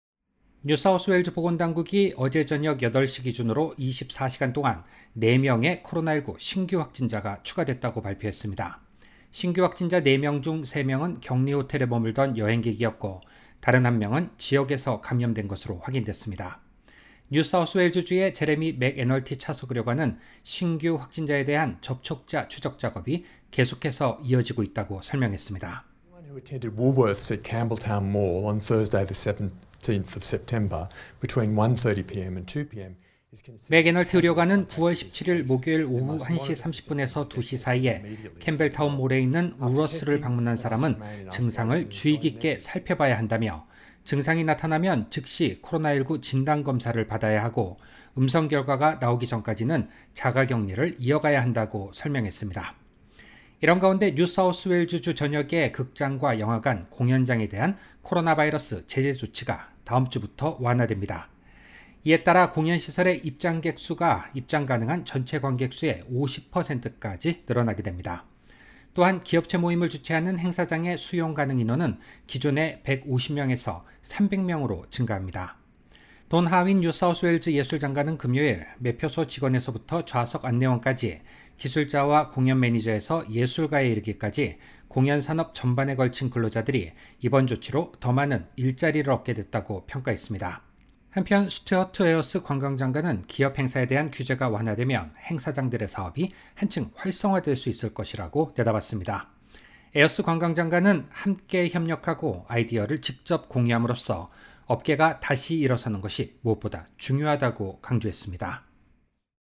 finalkorean_2509_audio_news_nsw_2.mp3